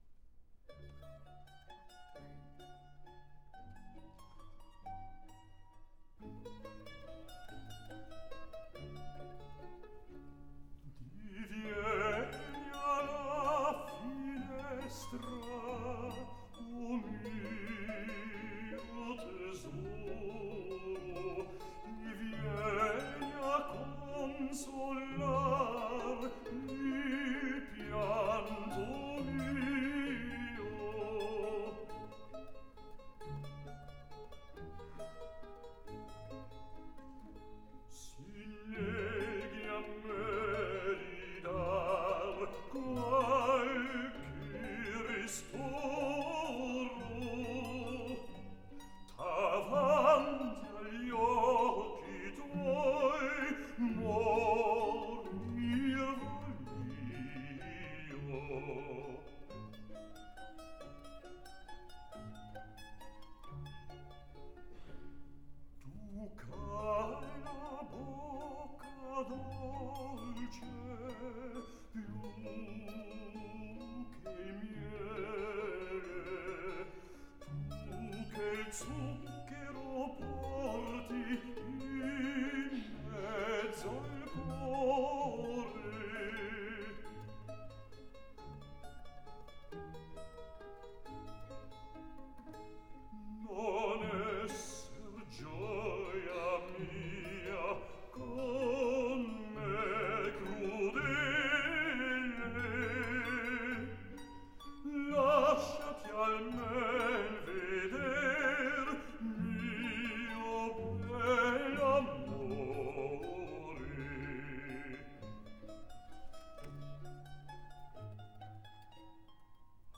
Canzonetta.